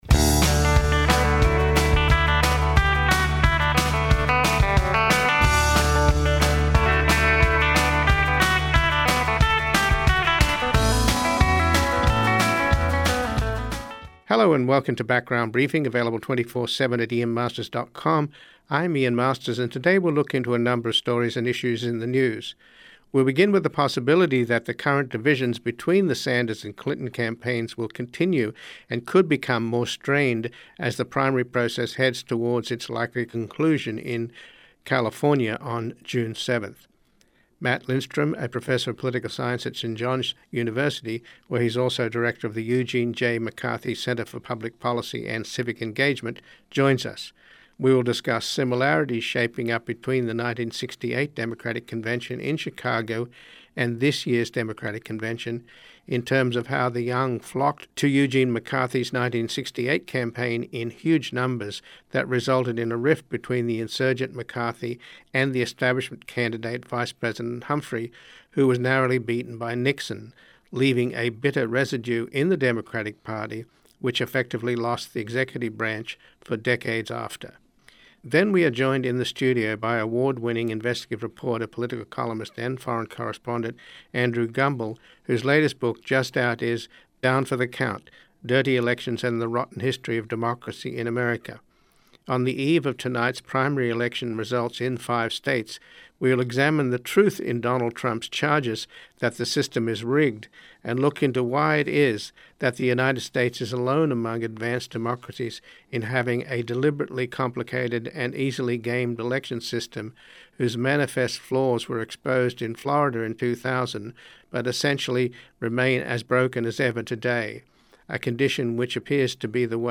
Then we are joined in the studio by award-winning investigative reporter, political columnist and foreign correspondent